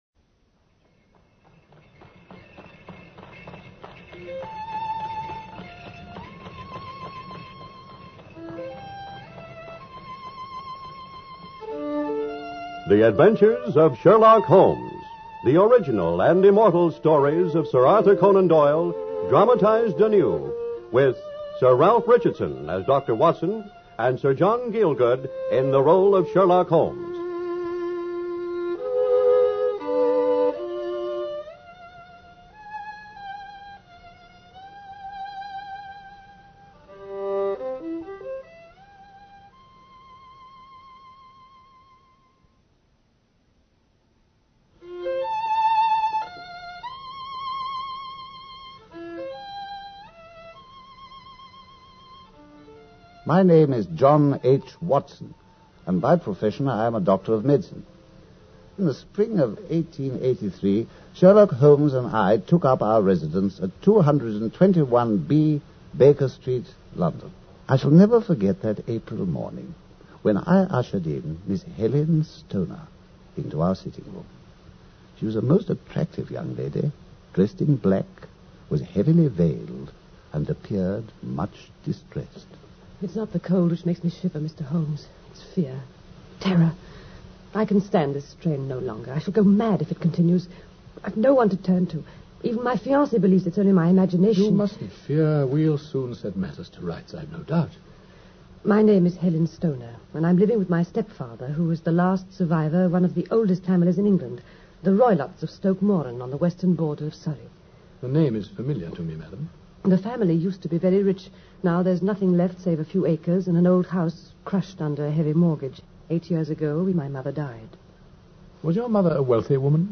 Radio Show Drama with Sherlock Holmes - The Speckled Band 1954